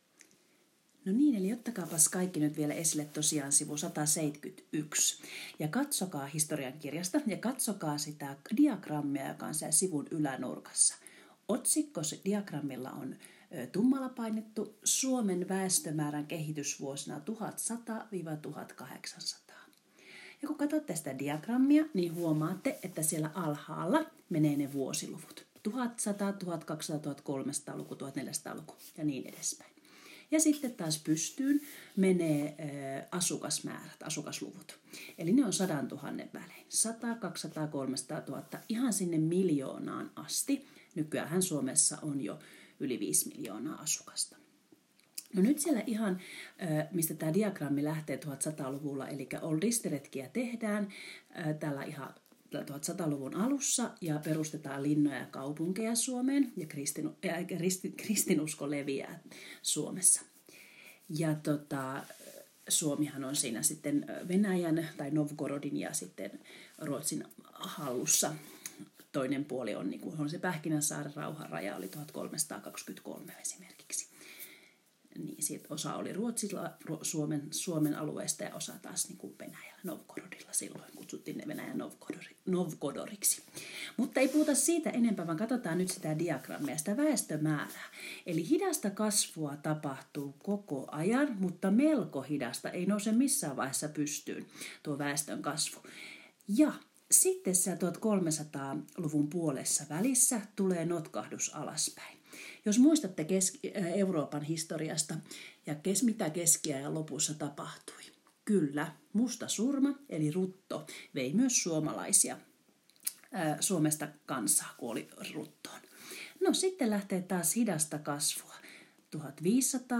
4. Kuuntele, mitä opettaja kertoo Suomen väestömäärään kehityksestä.